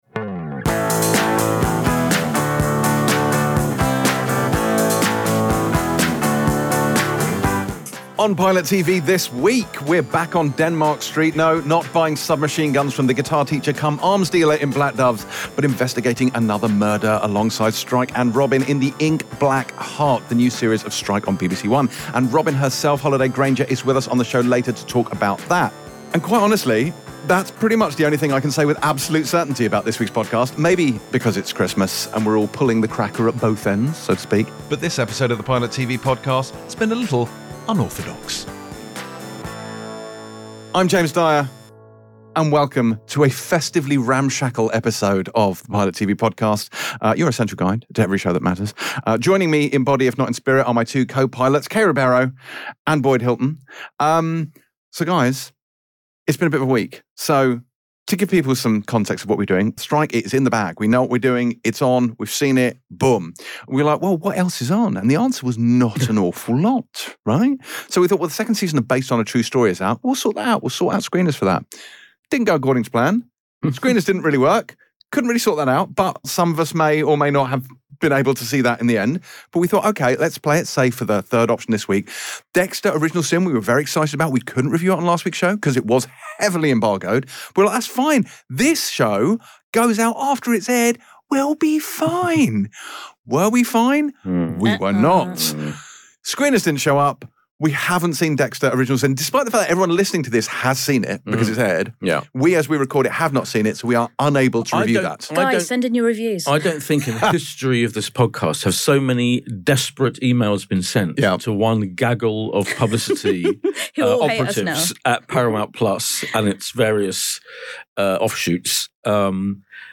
In an unexpected turn of events, Pilot TV’s set visit to the new third (and fourth) season of Apple TV+’s Silo resulted in a sit down interview with both Rebecca Ferguson and the big Apple himself, Tim Cook (27:24).